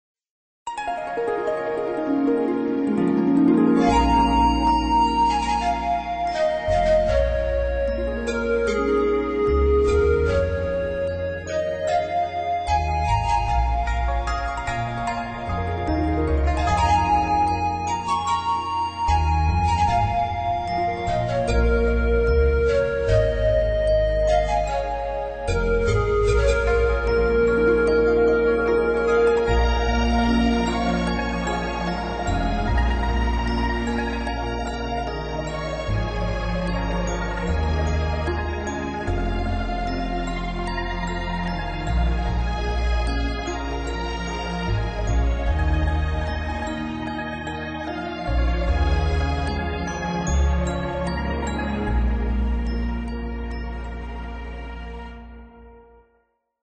【简介】： 中国风乐器、竖琴